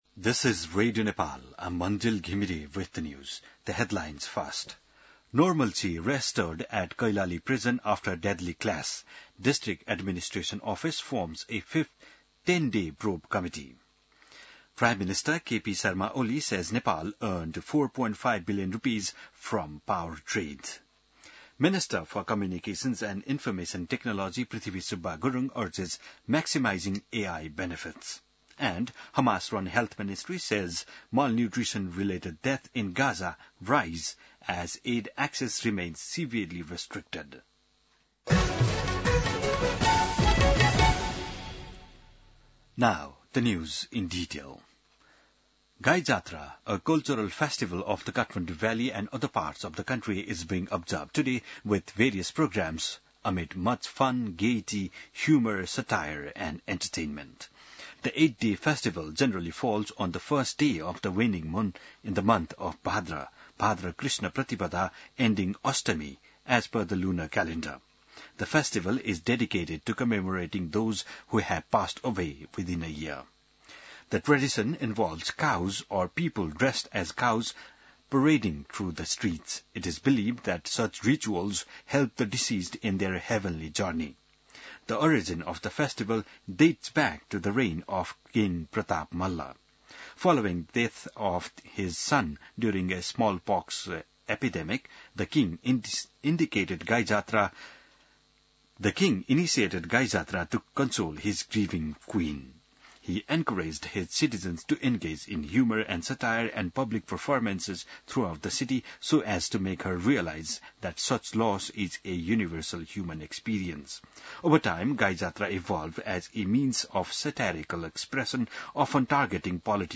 बिहान ८ बजेको अङ्ग्रेजी समाचार : २५ साउन , २०८२